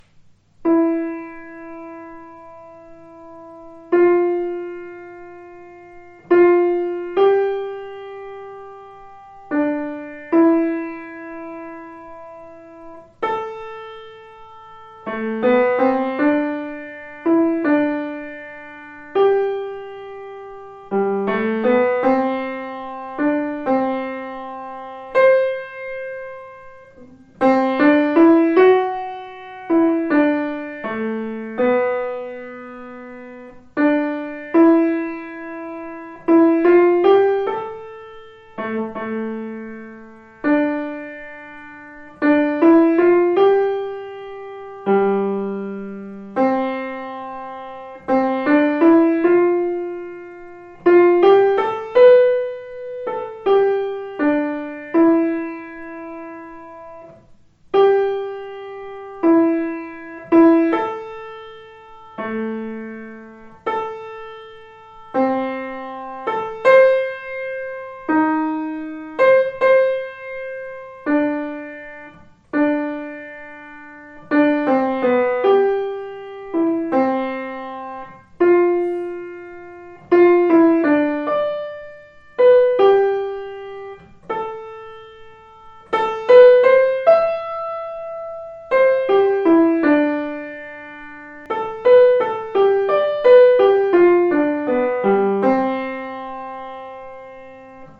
Klavier
Gounods Melodie oder Gounods Melodie mit Basston ohne harmonische Begleitung.